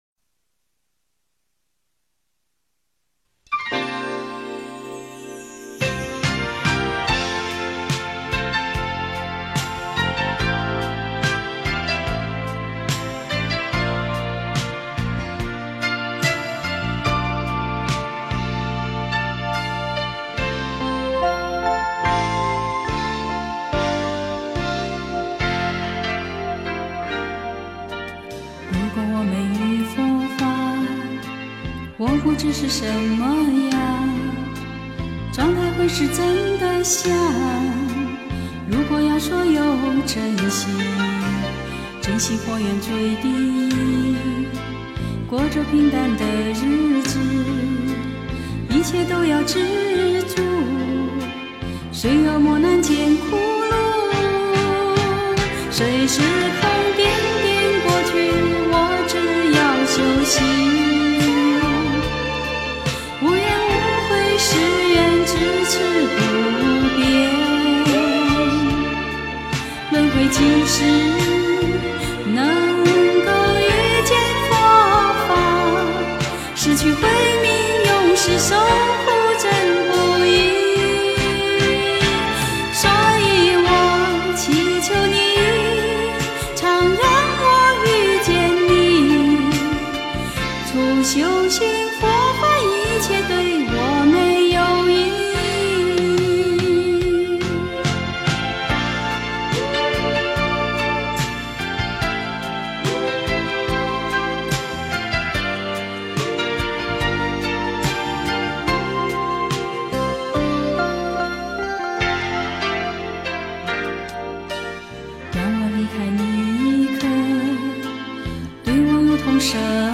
佛教音乐